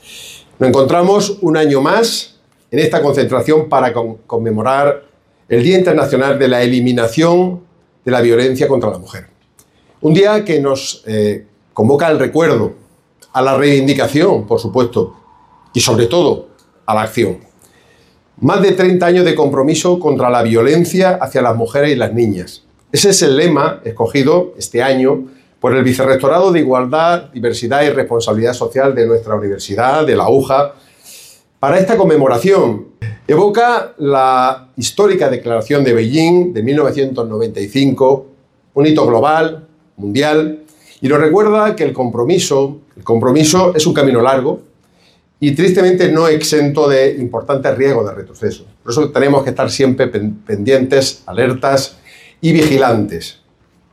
menu Declaraciones del Rector, Nicolás Ruiz, acerca del Día Internacional para la Eliminación de la Violencia contra las Mujeres < previous > next